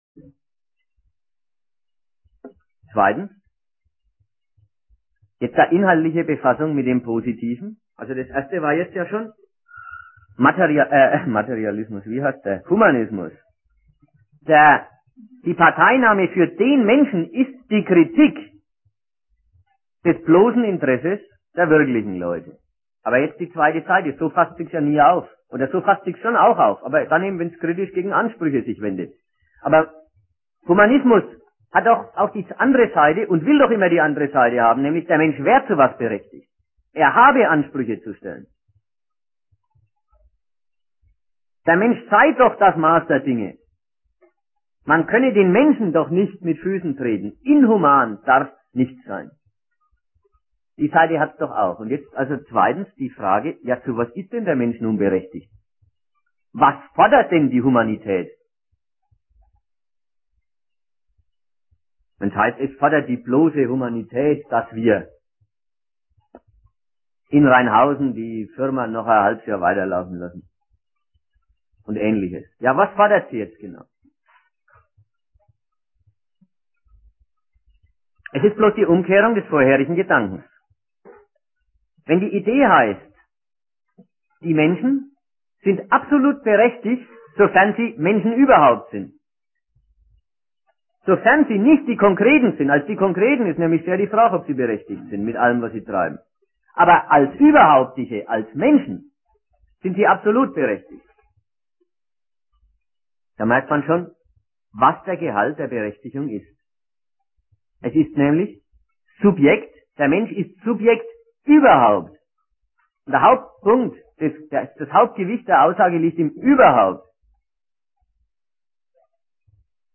Ort Erlangen
Dozent